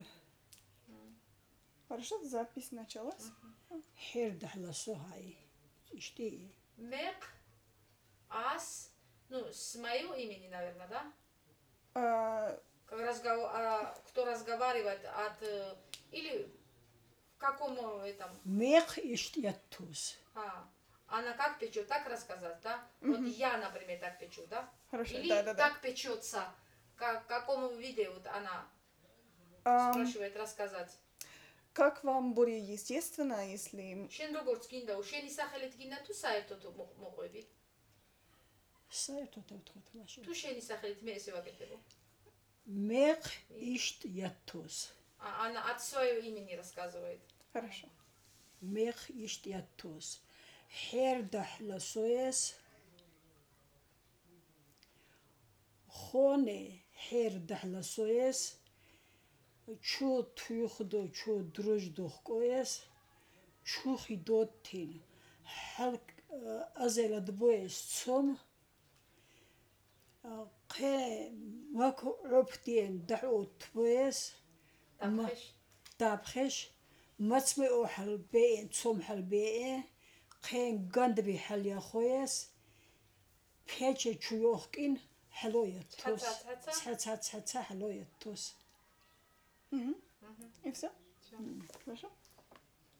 Speaker
Interviewer
digital wav file recorded at 48.1 kHz/24 bit on Zoom H2 solid state recorder with external Audio-Technica AT8033 cardioid condenser microphone
Zemo Alvani, Kakheti, Georgia